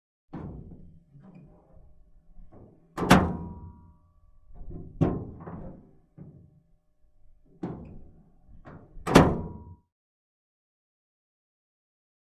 Pickup Truck Tail Gate Open / Close ( 2x ); Metal Tail Gate Movement, Metallic Ping With Close.
Close Perspective.